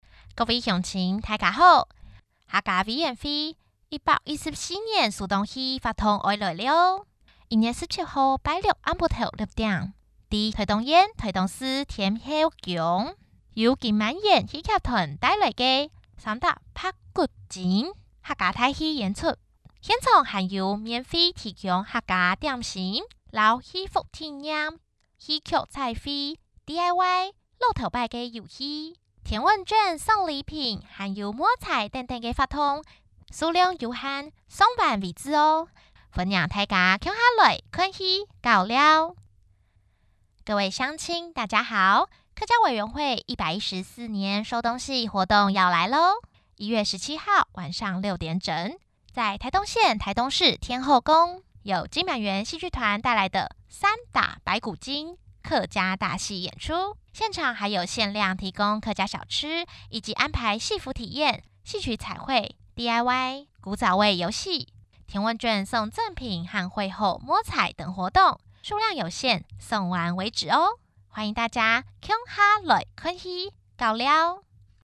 女性配音員 國語配音 客語配音員